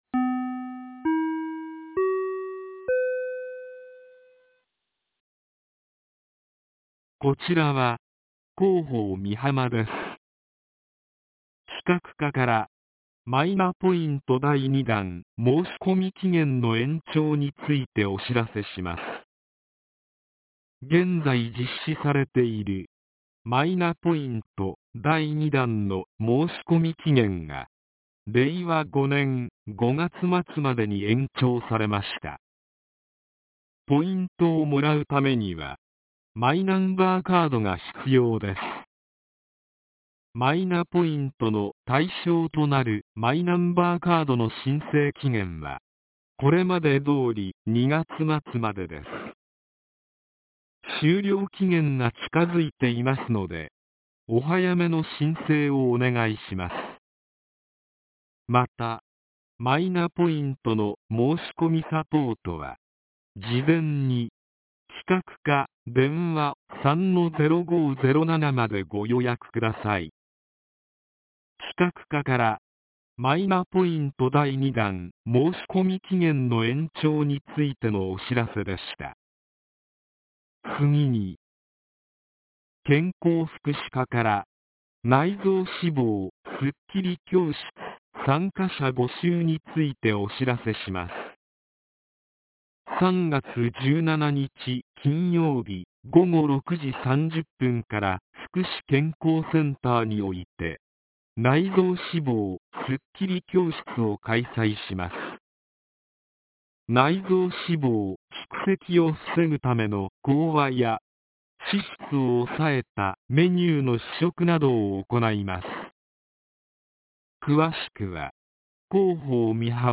■防災行政無線情報■